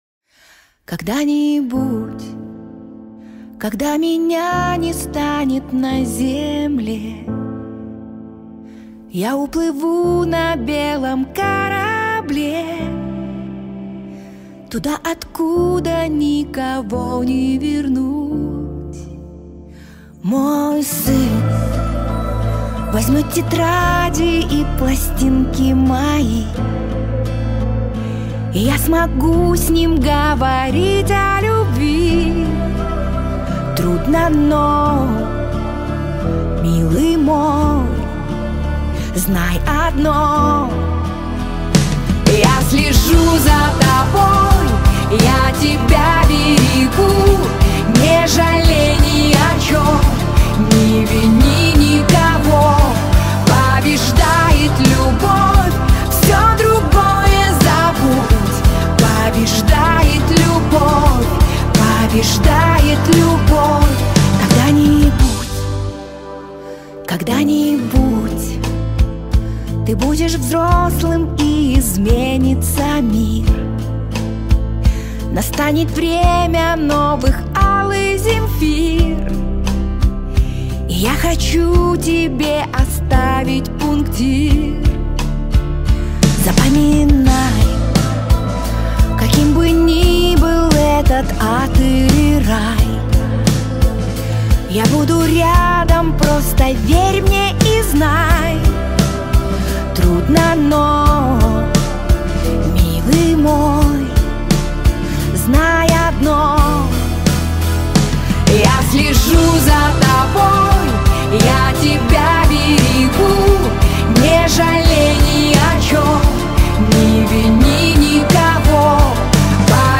трогательная баллада